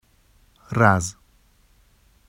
[raz] n garden